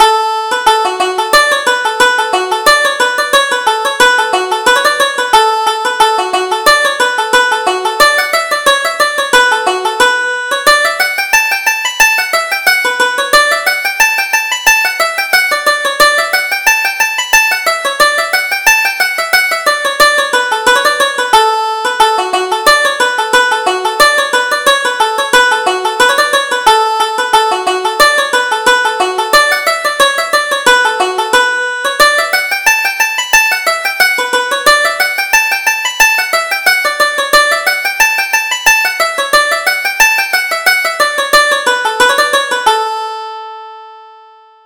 Reel: The Satin Slipper